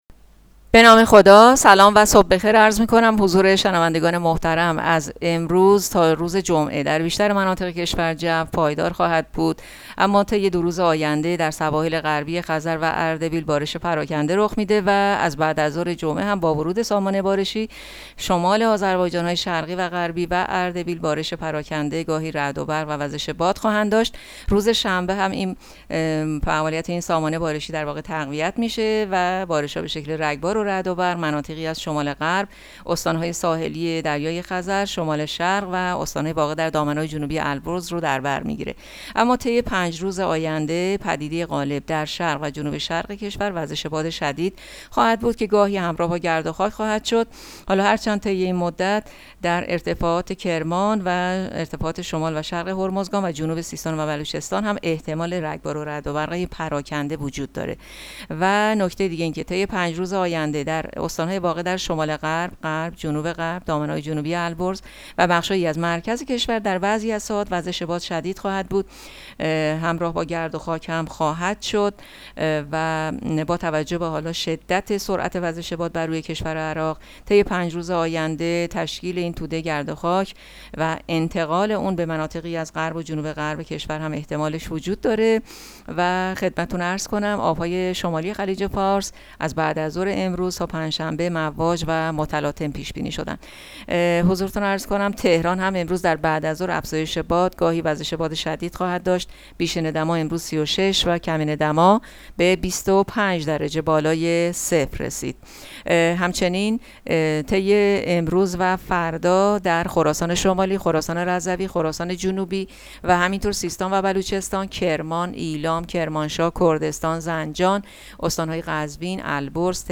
گزارش رادیو اینترنتی پایگاه‌ خبری از آخرین وضعیت آب‌وهوای ۳۰ اردیبهشت؛